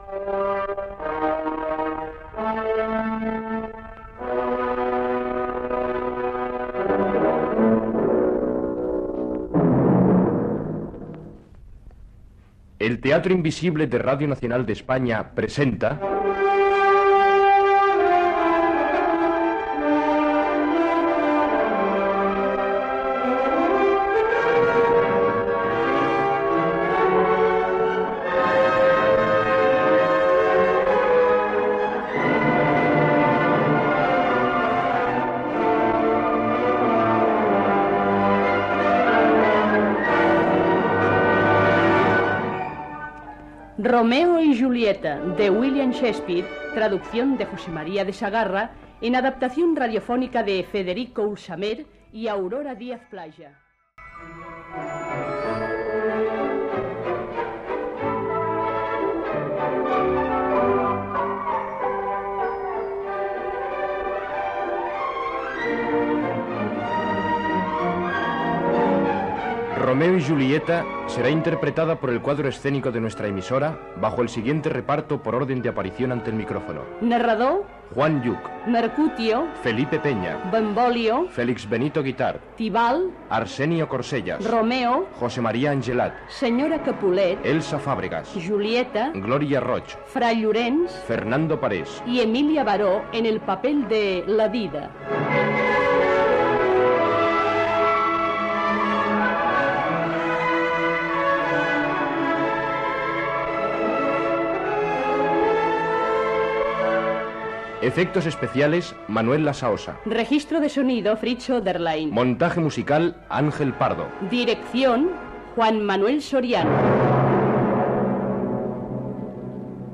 Adaptació de l'obra "Romeo y Julieta", e William Shakespeare. Careta amb el repartiment i primeres escenes de l'obra Gènere radiofònic Ficció